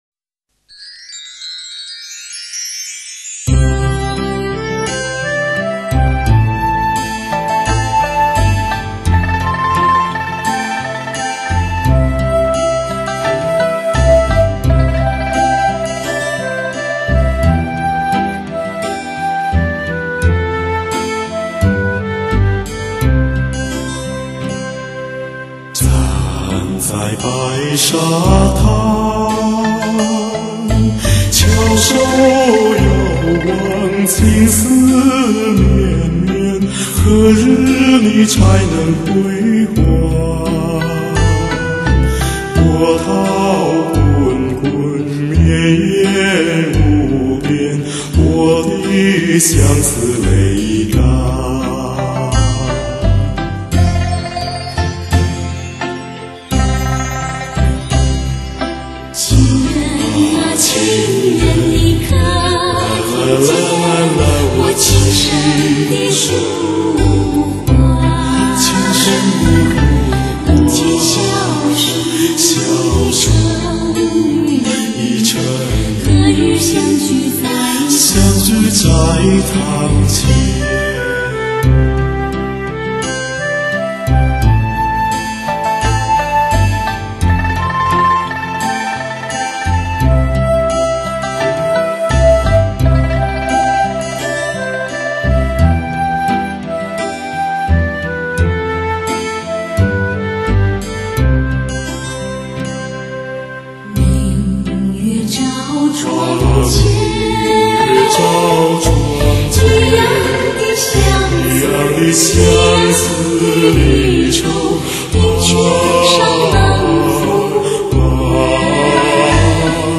中国目前最知名的演唱组合（男女声三重唱）
运用通俗唱法、美声唱法和民族唱法重新加以演绎
歌声像涓涓细流，轻轻缓缓，流进听者的耳中，直灌人们的心田。